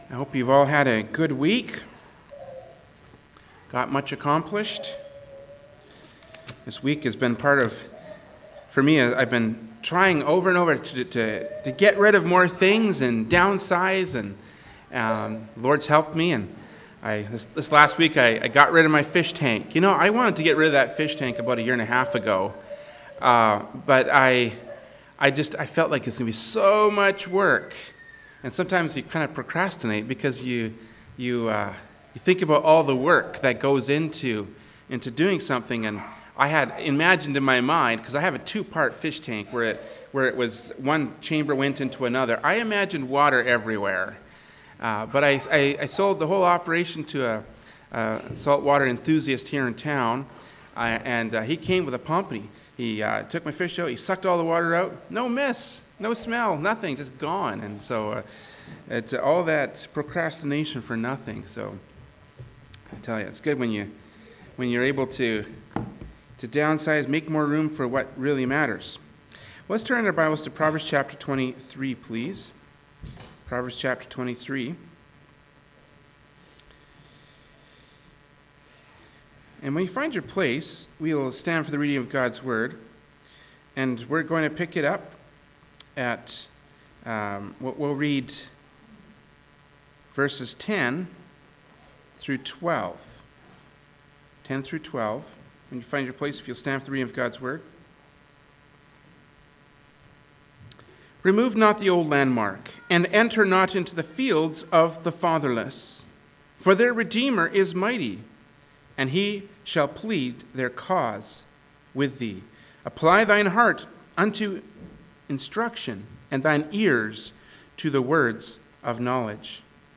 Genre: Preaching.
Service Type: Adult Sunday School